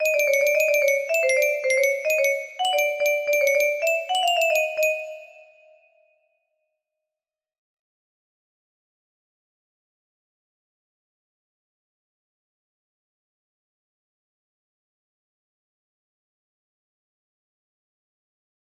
Power up music box melody